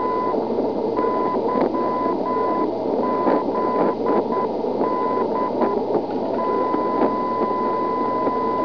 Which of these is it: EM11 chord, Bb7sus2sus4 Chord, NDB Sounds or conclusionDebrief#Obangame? NDB Sounds